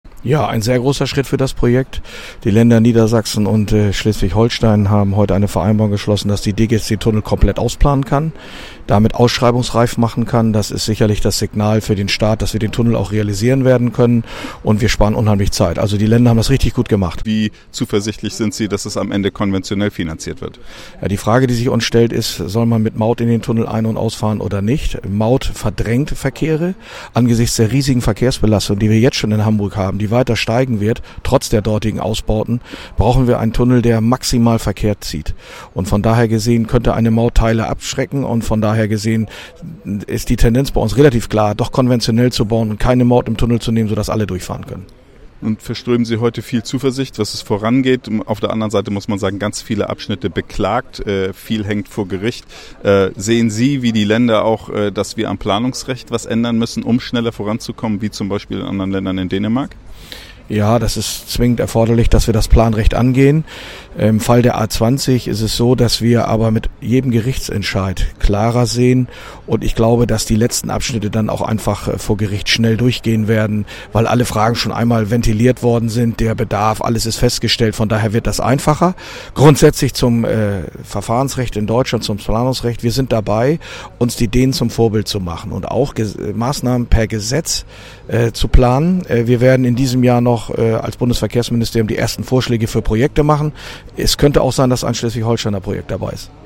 Im Gespräch mit Journalisten sagte Ferlemann weiter (Audio starten – Pfeil klicken)
ferlemann1.mp3